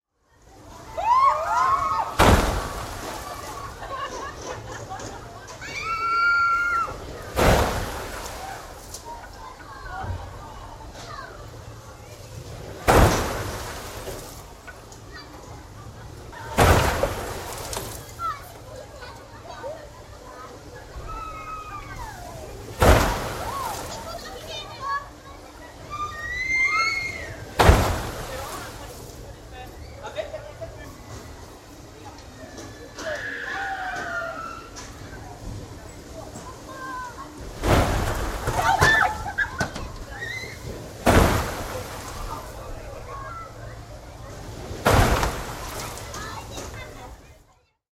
Звуки аквапарка
Звук прыжков с водной горки в аквапарке